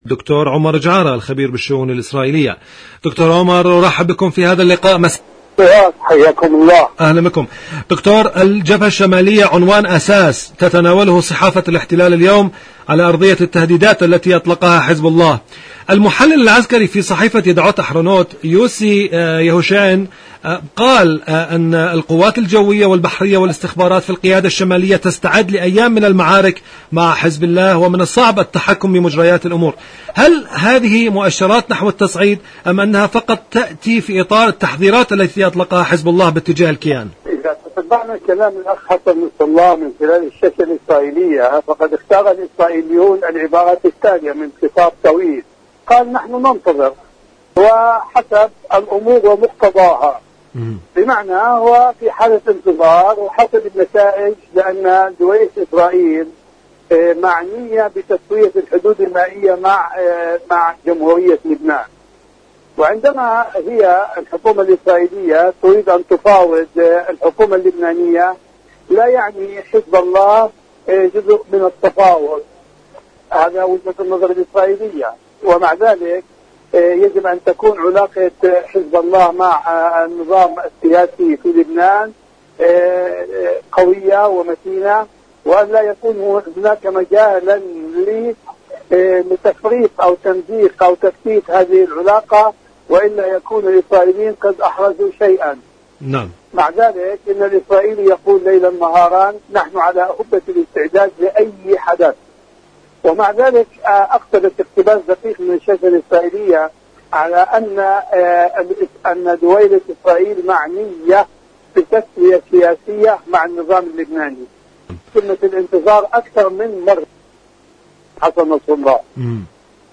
إذاعة طهران-فلسطين اليوم: مقابلة إذاعية